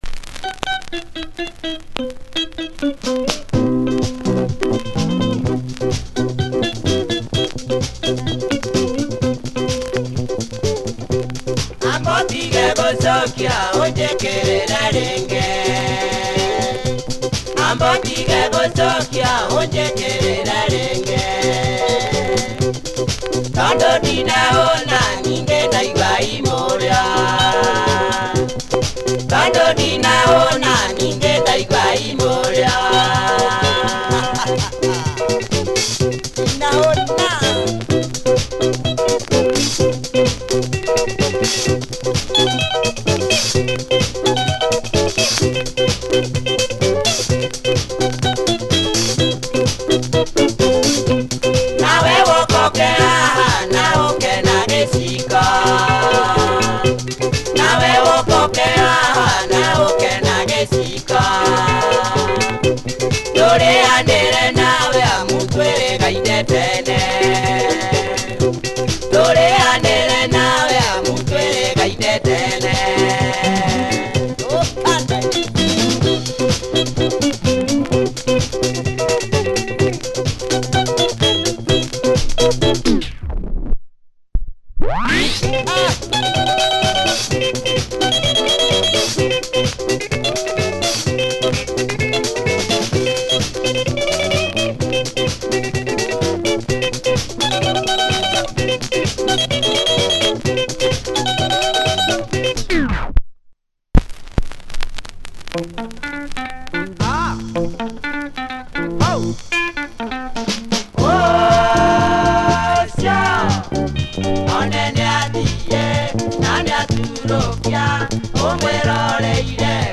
Great funky Kikuyu benga double sider